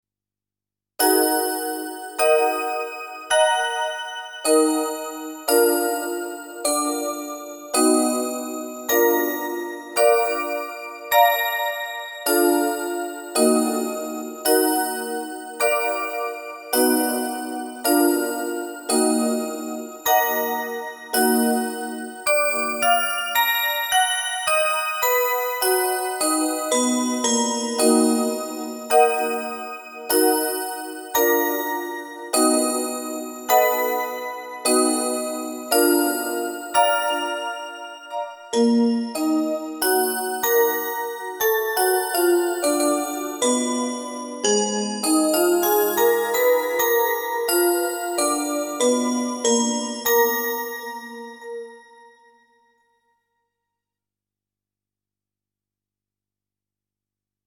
In raag Gauri Rishab and Dhaivat are komal surs.
Bells:
bells_gauri.mp3